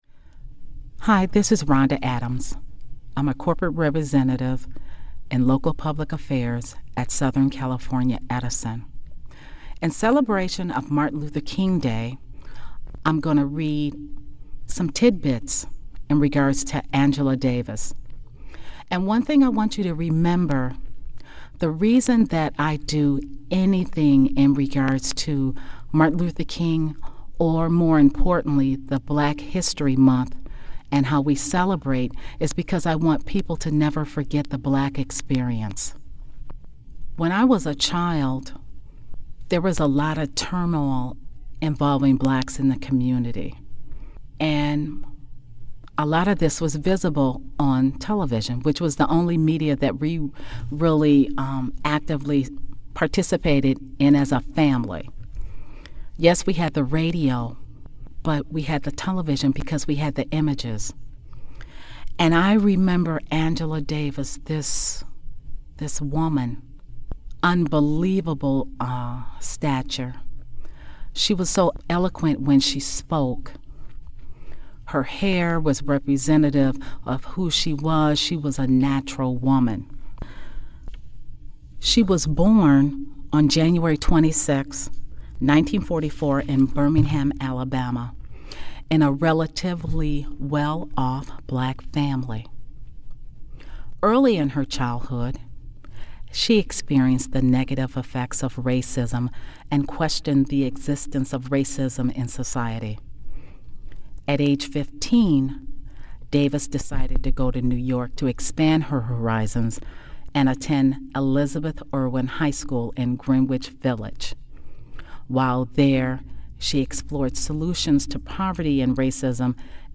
BHM+Angela+Davis+Reading.mp3